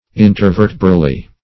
Between vertebr[ae]. -- In`ter*ver"te*bral*ly , adv.